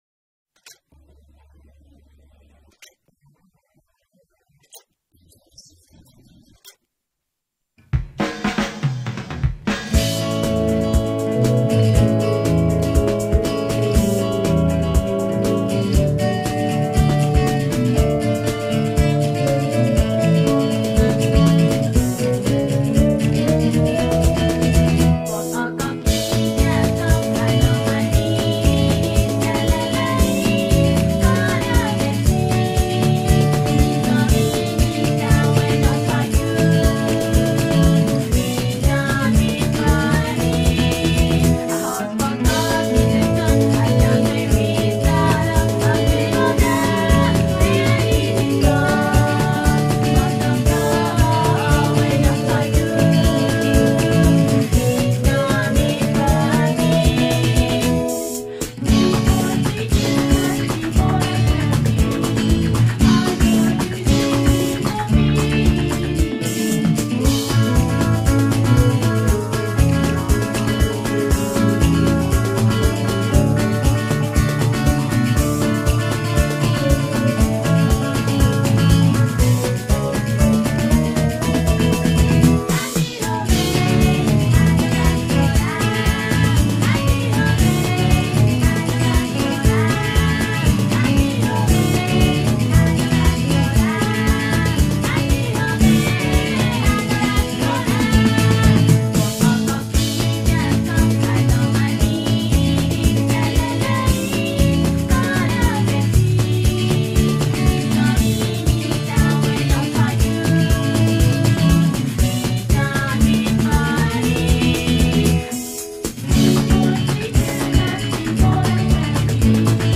vocals guitar, tambourine and keyboard
bass, backing vocals
drums, percussion